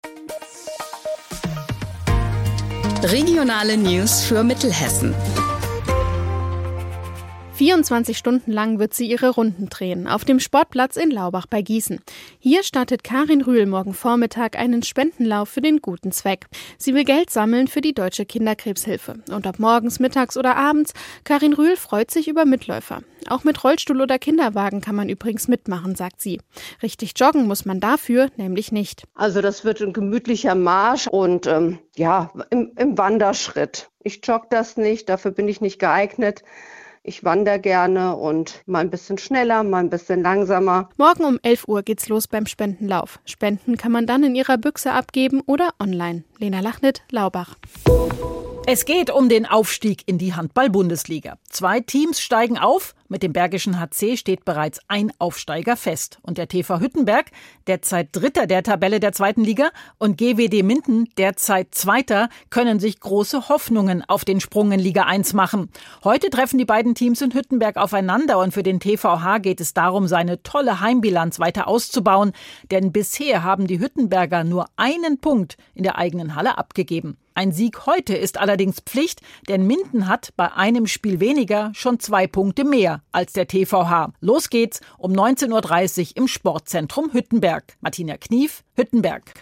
Mittags eine aktuelle Reportage des Studios Gießen für die Region.